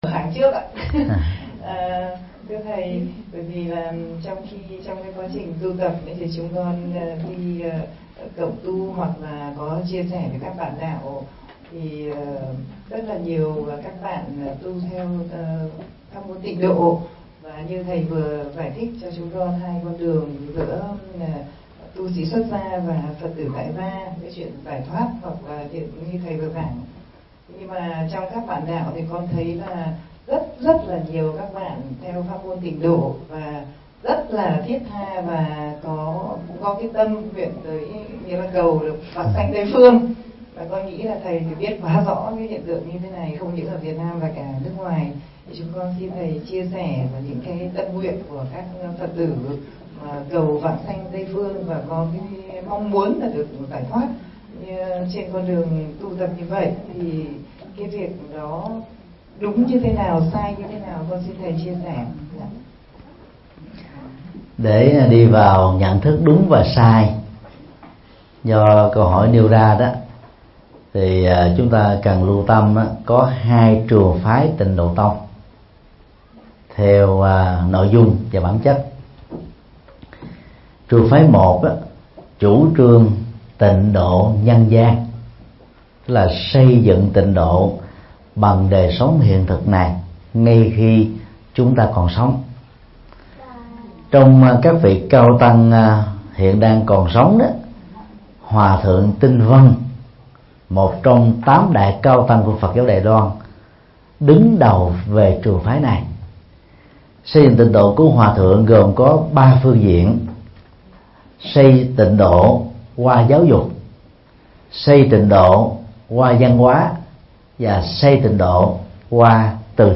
Vấn đáp: Cầu vãng sanh Tây Phương
Giảng tại Niệm Phật đường Giác Tuệ, Dresden, Đức